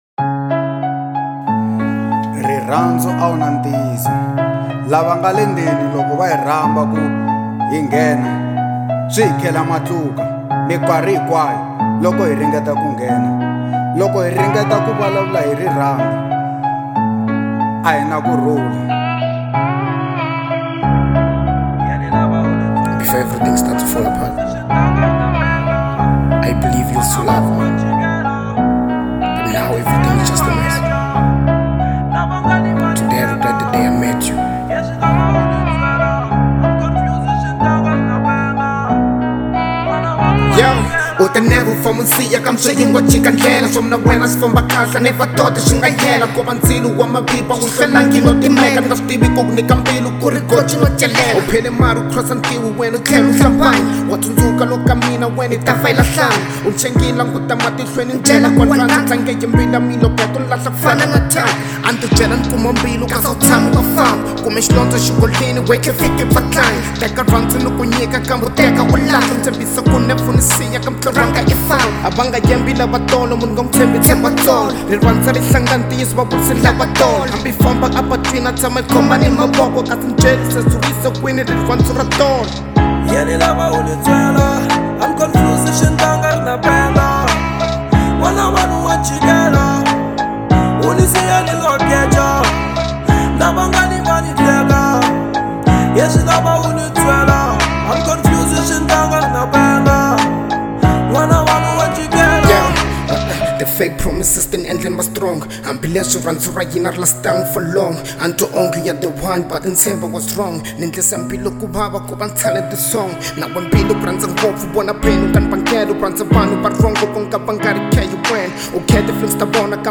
Genre : RnB